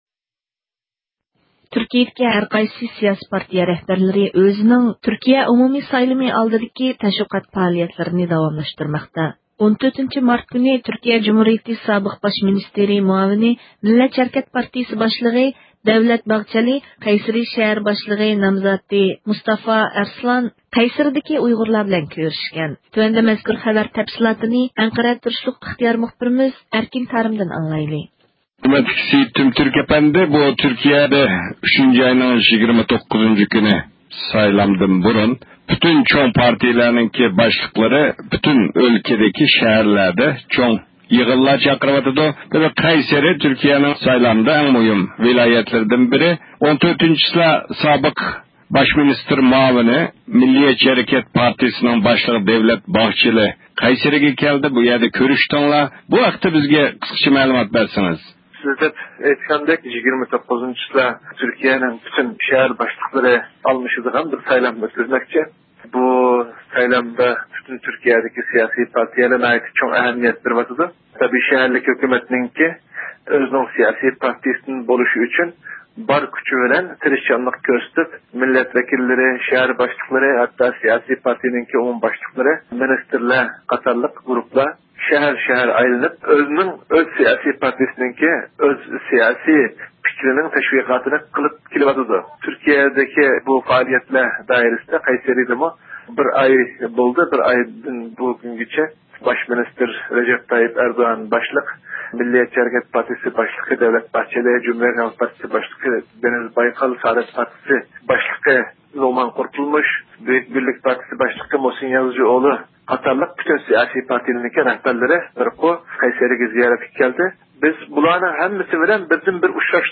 تېلېفون زىيارىتى ئېلىپ باردۇق.